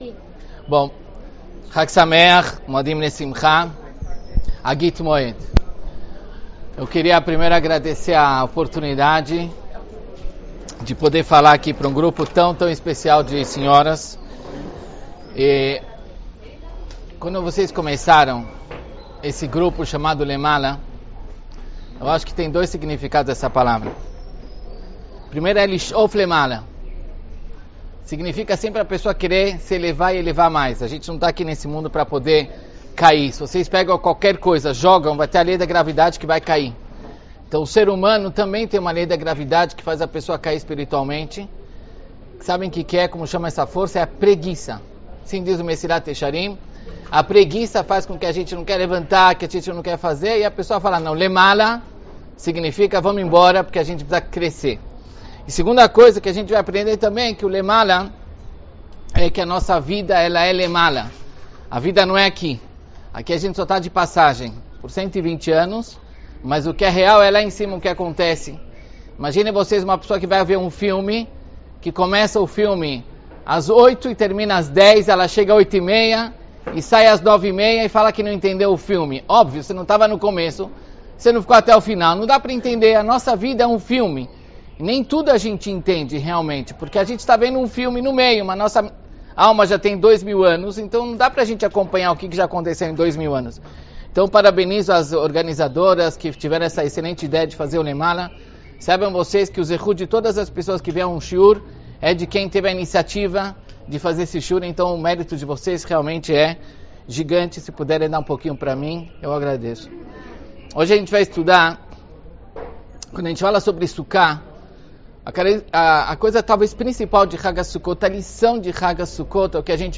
Emuna e Bitachon, Shiur na Sukka – LEMALA